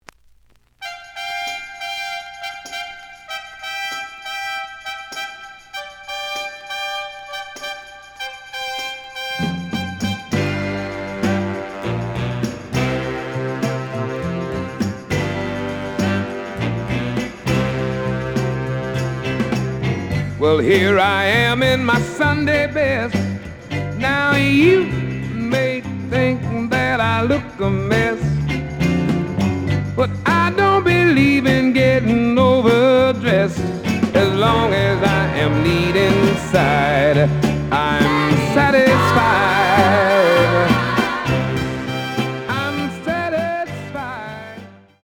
The audio sample is recorded from the actual item.
●Genre: Soul, 60's Soul
Slight edge warp. But doesn't affect playing. Plays good.